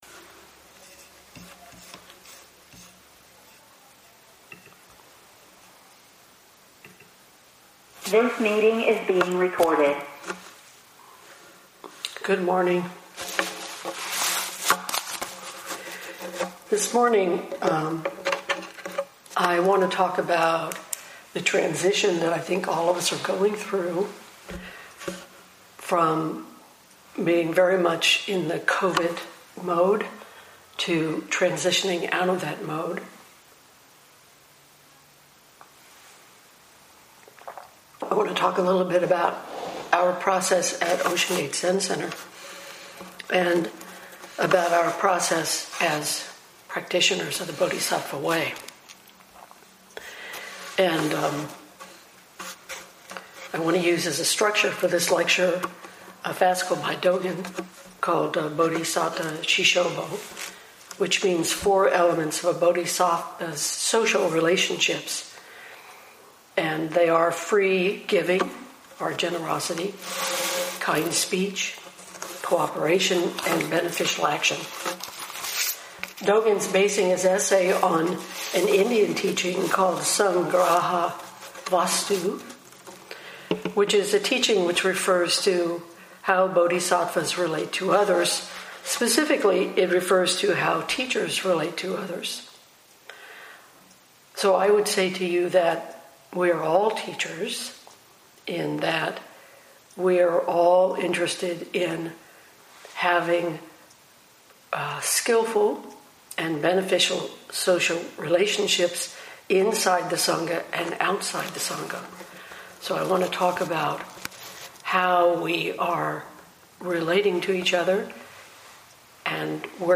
2021 in Dharma Talks